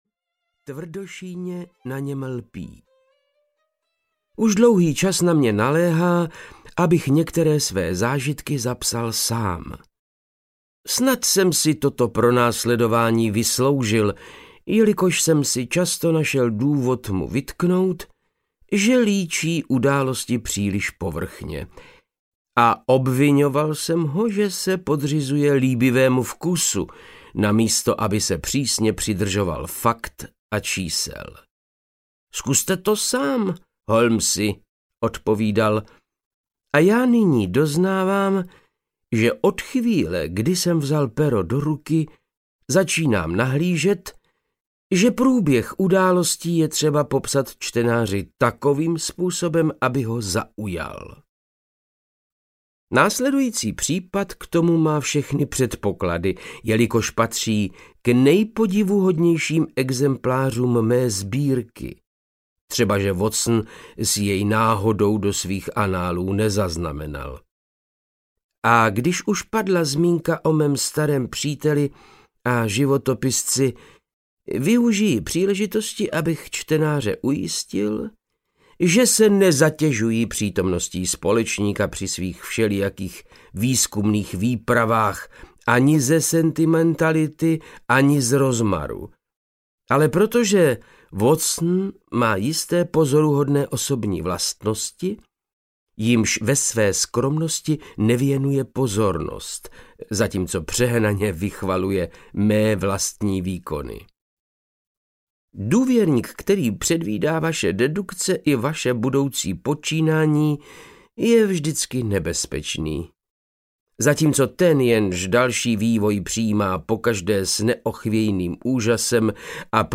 Voják bílý jako stěna audiokniha
Ukázka z knihy
• InterpretVáclav Knop